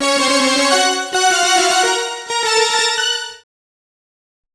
Synth Lick 49-06.wav